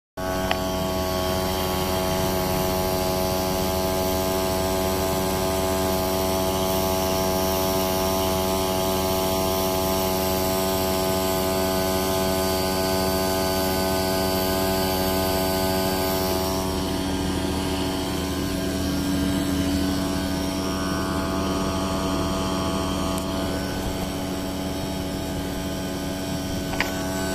Je constate toujours comme un bruit de sifflement dans l'UE, et dans l' UI la plus grosse (7.1kW) on entend ce sifflement et elle fait légèrement "glou glou" comme si on entendait le liquide qui passait dans la liaison (en mode chaud en tout cas).
"froid à fond" et "chaud à fond", le plus bruyant étant le chaud à fond. Si on écoute bien, derrière le bruit de mobylette (compresseur ?) on entend le sifflement dont je parle.